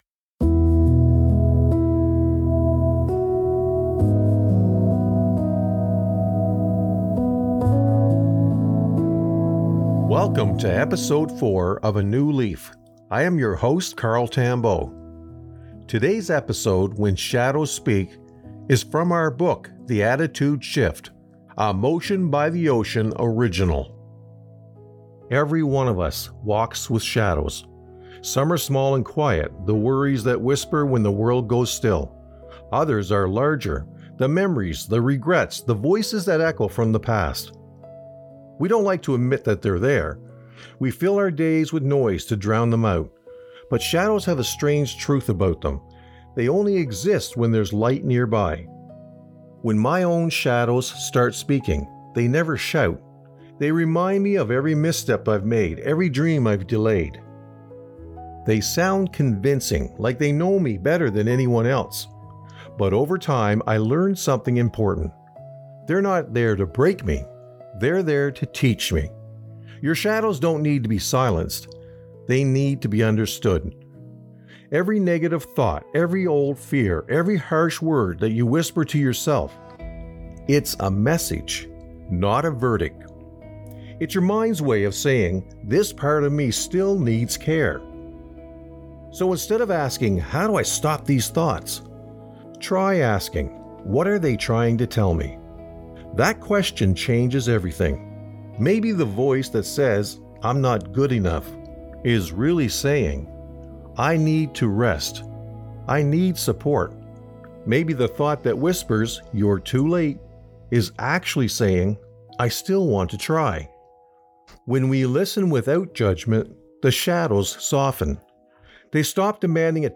A calm, deeply human reflection for anyone navigating self-doubt, emotional heaviness, or the quiet hours of the night.